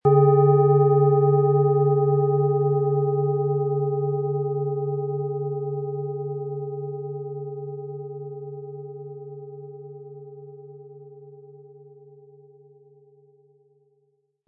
Tibetische Bauch- und Universal-Klangschale, Ø 17,4 cm, 500-600 Gramm, mit Klöppel
HerstellungIn Handarbeit getrieben
MaterialBronze